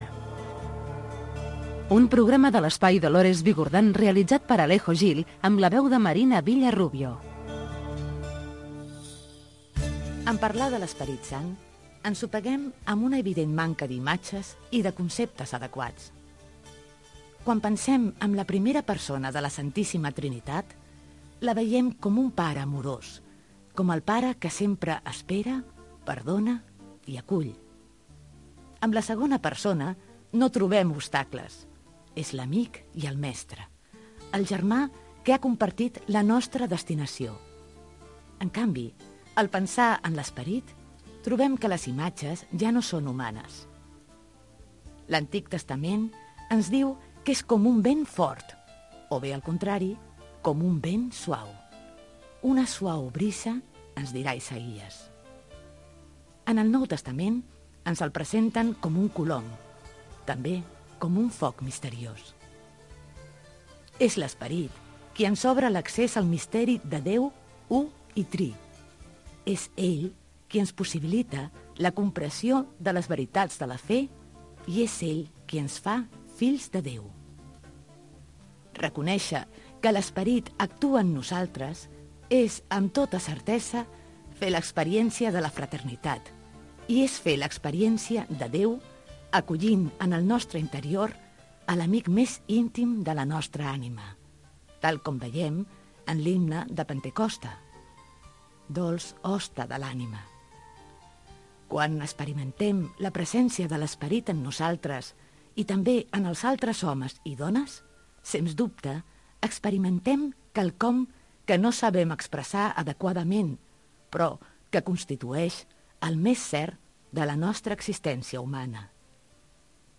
Espai religisiós de l'Espai Dolores Bigurdan dedicat a l'esperit sant, indicatiu de l'emissora, promoció del programa "Accent", publicitat, promocions de "Els sons de la terra" i "Trotamundos", careta del programa "Tradicionarius" i tema musical
Musical
FM